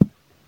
beeb kick 9
Tags: 808 drum cat kick kicks hip-hop